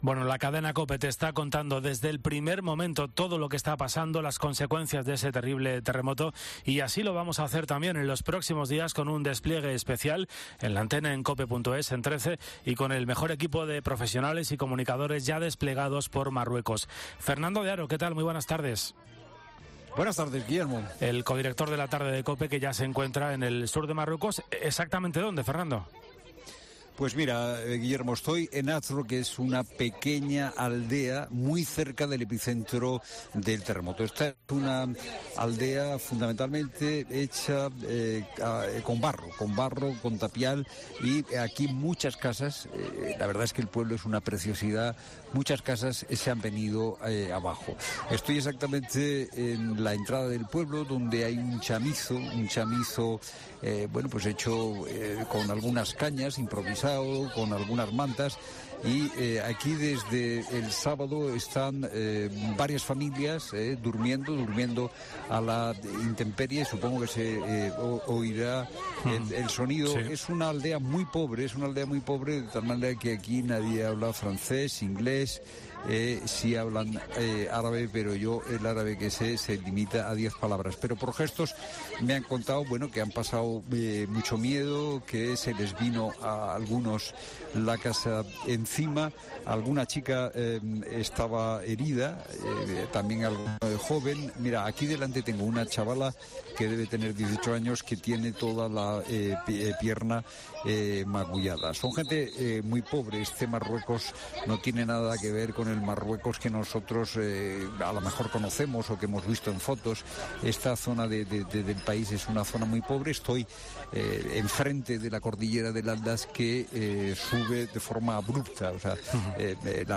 Fernando de Haro, desde Marrakech
Allí se encuentra ya el codirector de La Tarde de COPE, Fernando de Haro que le ha contado a Cristina López Schlichting sus primeras impresiones nada más aterrizar en Marrakech.